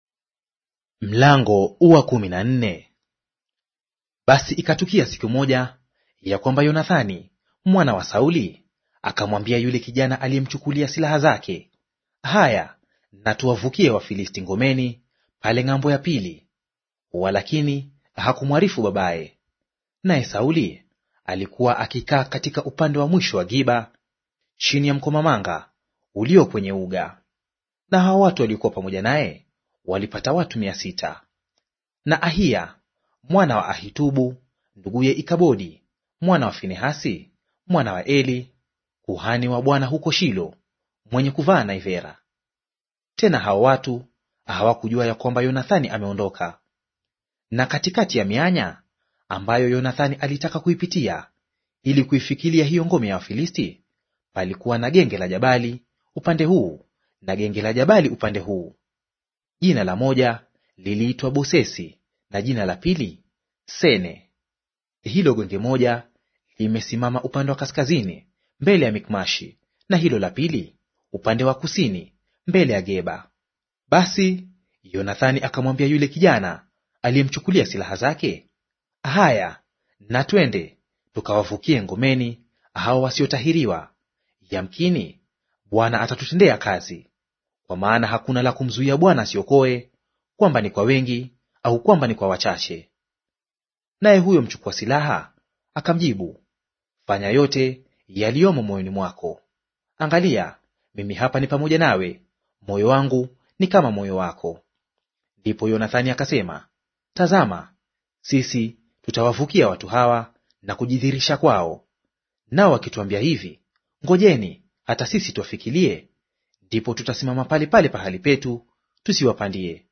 Audio reading of 1 Samweli Chapter 14 in Swahili